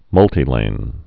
(mŭltē-lān, -tī-)